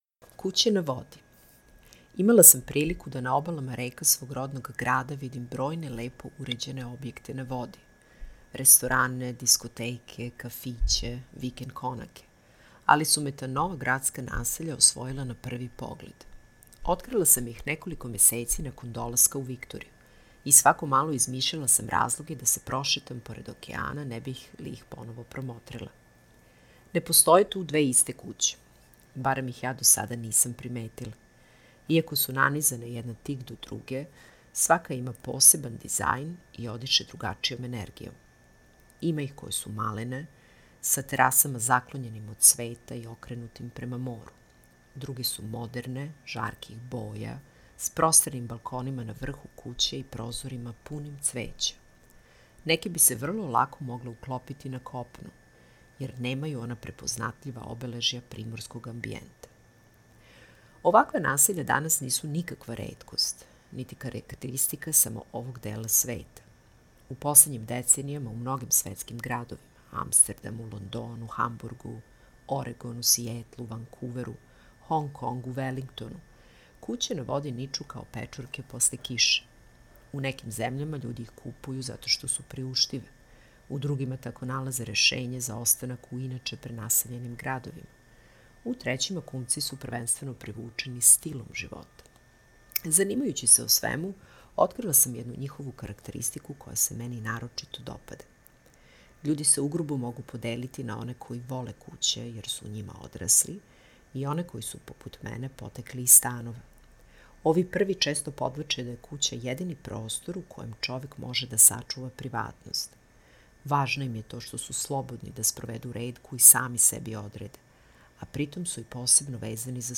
Audio zapis teksta: